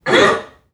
NPC_Creatures_Vocalisations_Robothead [26].wav